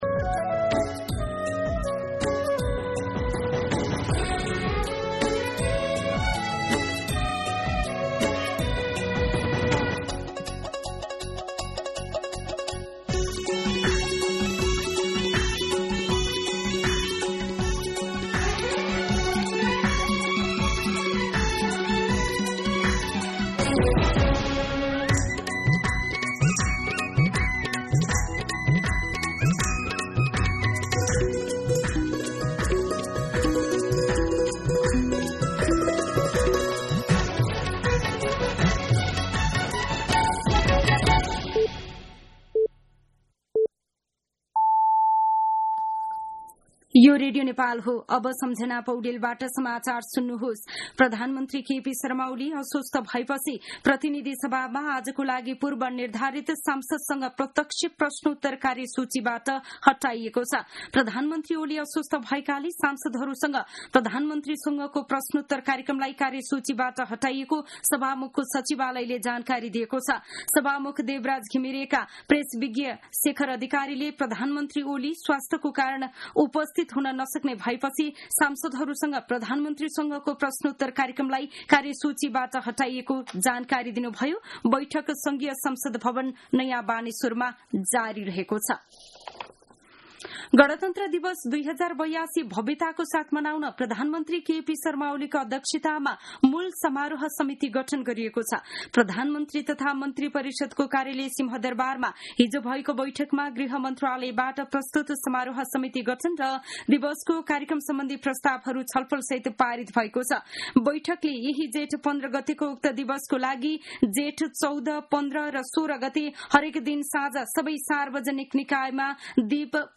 मध्यान्ह १२ बजेको नेपाली समाचार : ६ जेठ , २०८२